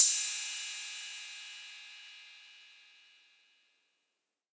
• Crash Sound Clip F Key 19.wav
Royality free crash cymbal tuned to the F note. Loudest frequency: 9493Hz
crash-sound-clip-f-key-19-CTz.wav